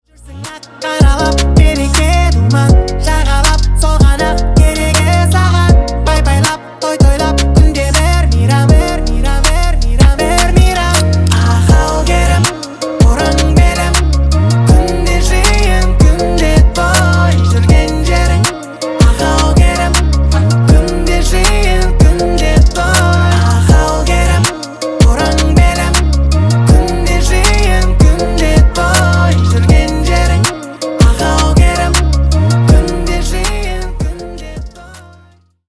бесплатный рингтон в виде самого яркого фрагмента из песни
Поп Музыка